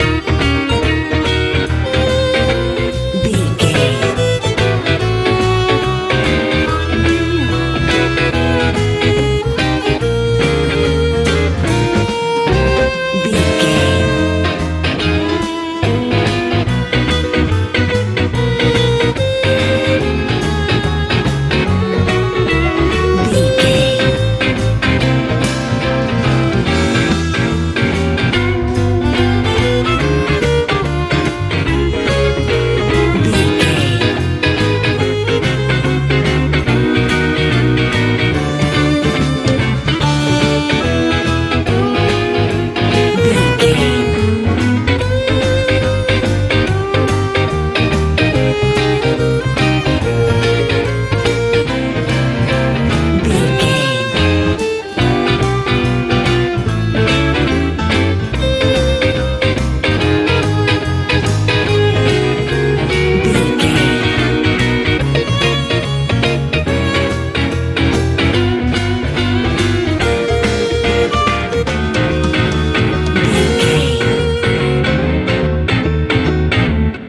roping texas feel
Ionian/Major
E♭
driving
groovy
drums
bass guitar
violin
electric guitar
piano
joyful
intense
lively